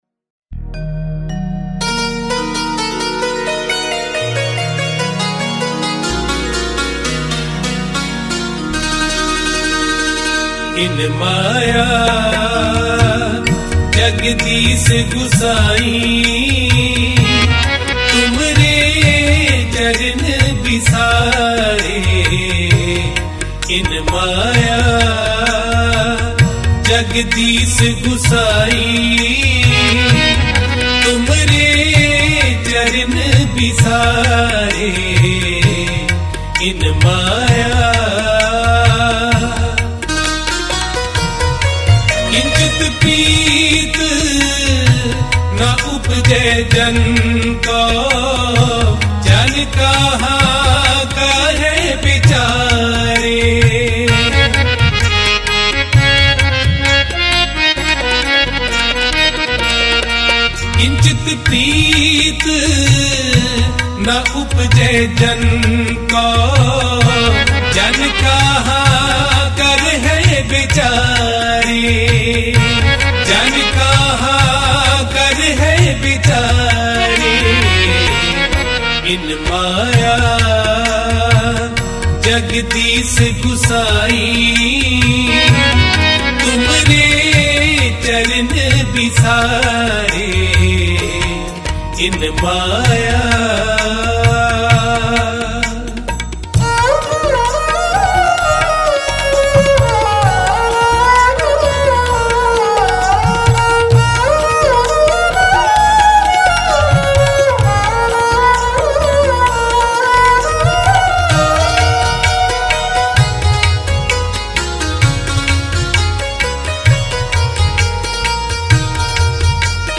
Mp3 Files / Gurbani Kirtan / 2025 Shabad Kirtan /